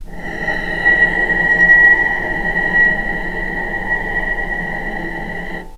vc_sp-B5-pp.AIF